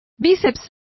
Complete with pronunciation of the translation of bicepses.